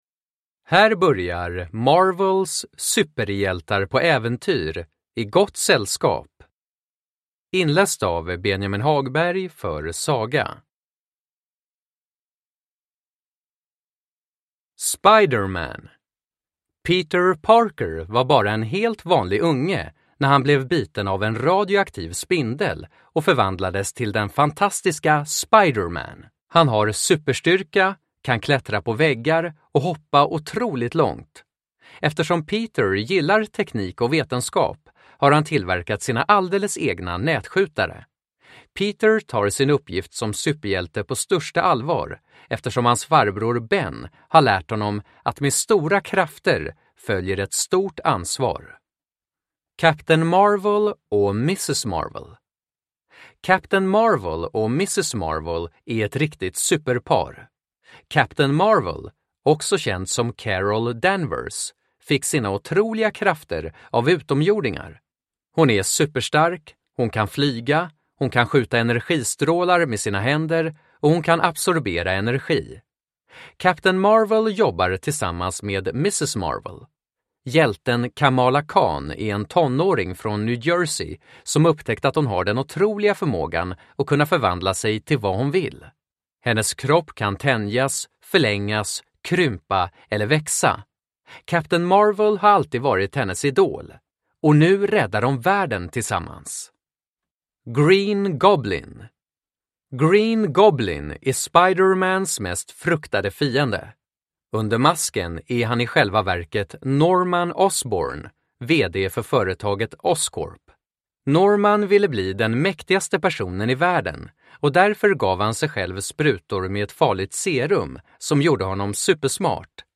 Marvel - Superhjältar på äventyr - I gott sällskap! (ljudbok) av Marvel